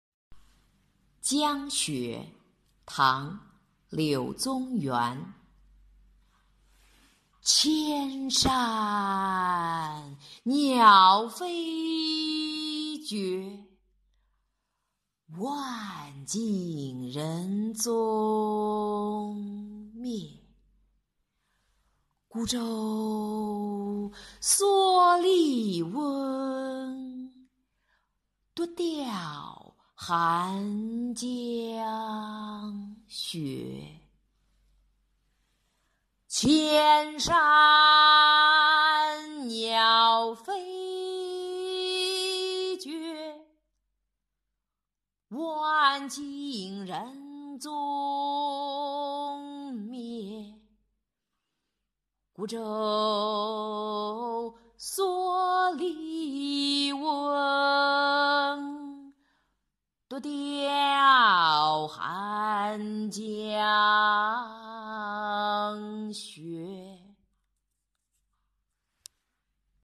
江雪—古诗吟诵